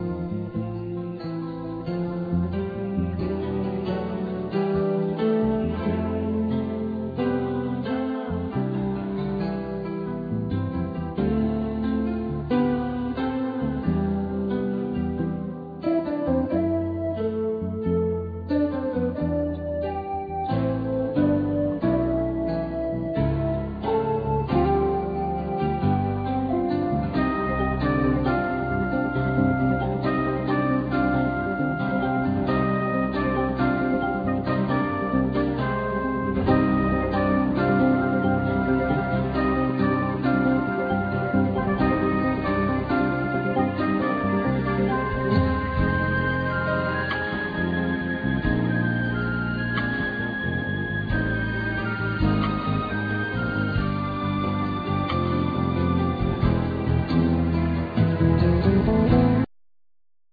Bass,Ac.guitar,Percussions,Vocal
Synthesizer,Percussions,Vocal
Flute,Percussions,Sopranosaxophne
Drums
Piano